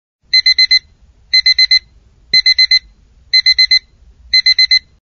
تحميل منبه الاستيقاظ القوي للهاتف🔊⏰ Alarm-Clock
لتحميل نغمة منبه الساعة القديم و المشهور للهاتف المحمول مجانا MP3, تنزيل نغمة منبه الاستيقاظ بصوت عالي وقوي لمقاومة غلبة النعاس والتأخر عن الدراسة و العمل, قم بتنزيل نغمة منبه الساعة القديمة لهاتفك المحمول Alarm-Clock MP3 ⏰, قم بالاستماع له تحت أو قم بتنزيل نغمات منبه عالية الصوت أخرى في: أقوى 15 منبه إستيقاظ عالي الصوت.
AlarmClock.mp3